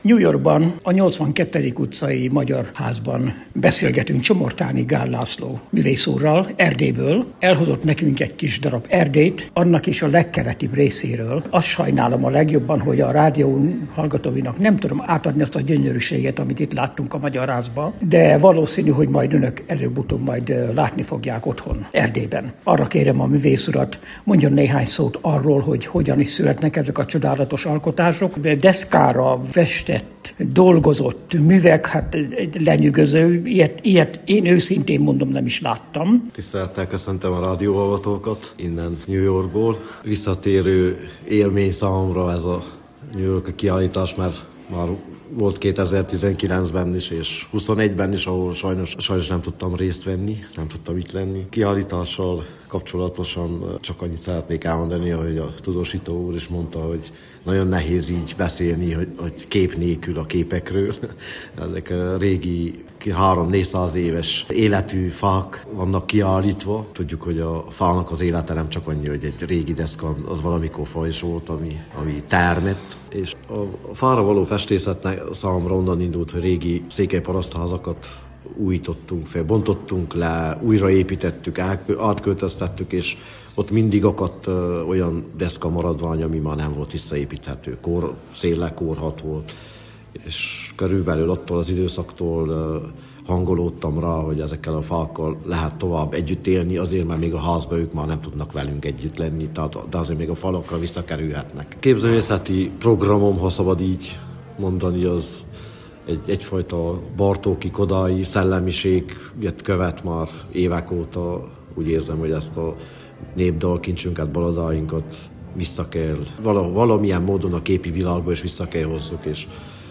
A New Yorki Magyar Házban ott volt a megnyitón